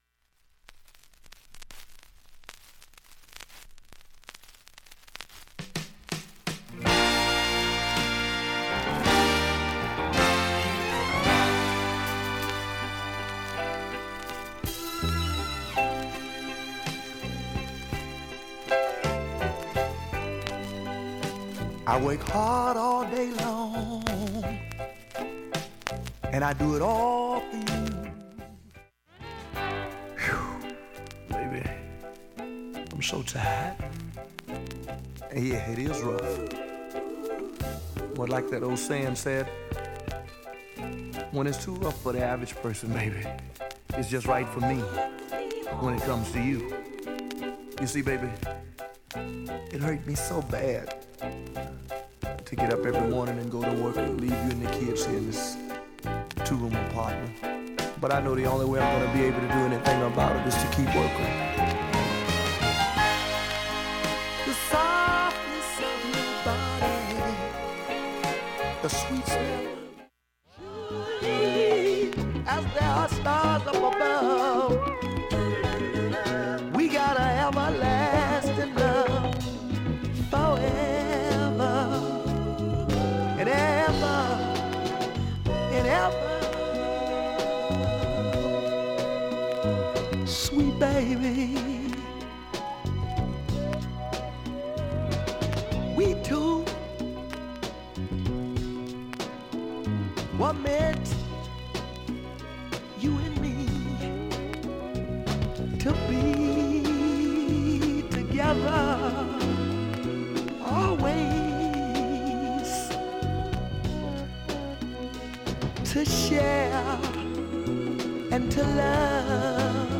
各面始めにかすかなサーフェス出ますが
普通に音質良好全曲試聴済み。
A-3中盤にかすかなプツが２０回出ます。
メロウ・ソウル